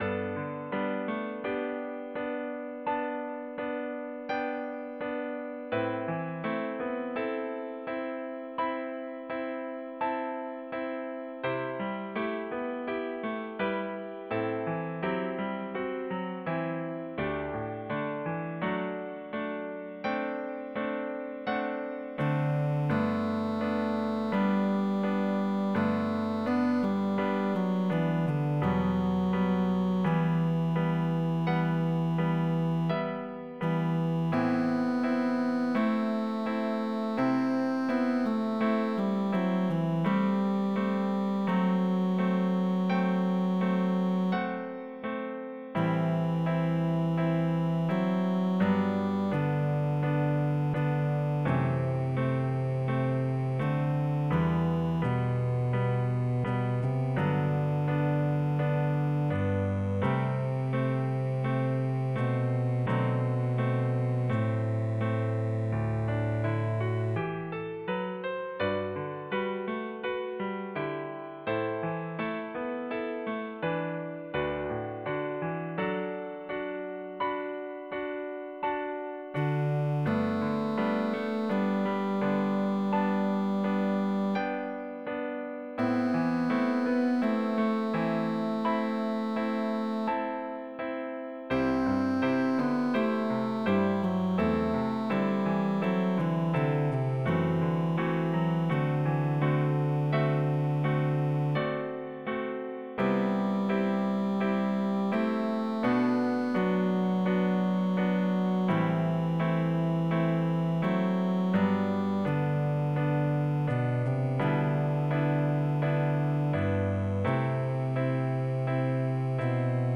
Voicing/Instrumentation: Cello solo